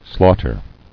[slaugh·ter]